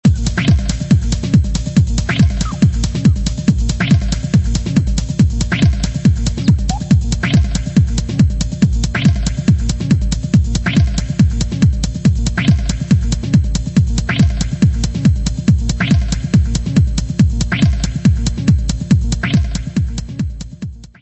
: stereo; 12 cm
Music Category/Genre:  Pop / Rock